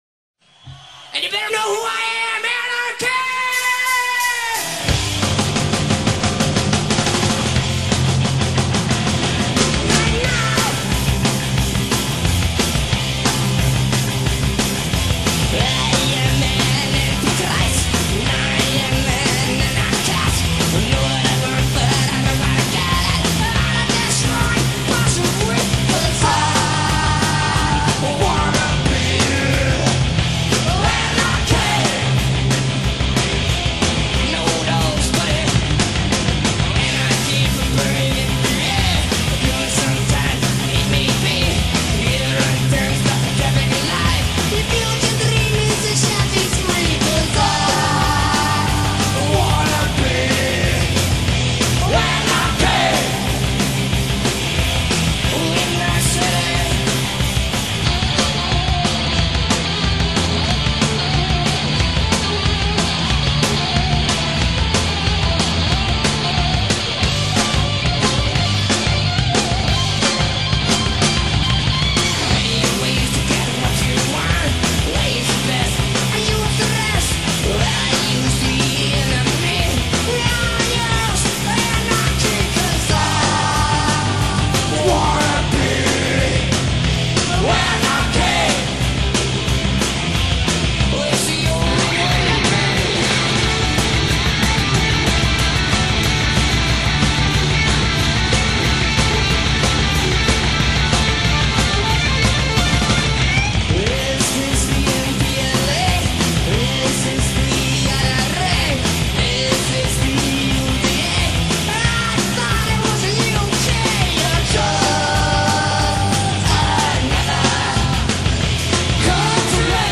Live '90